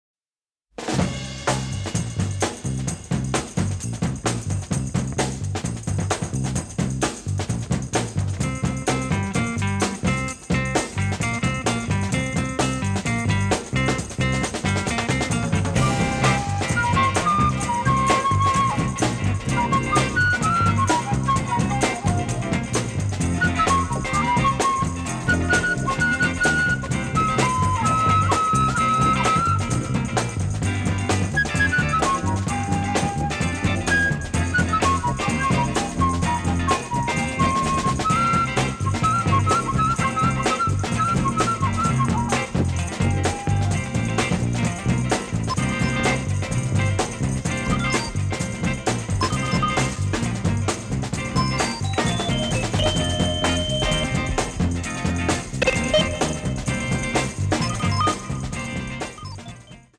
shake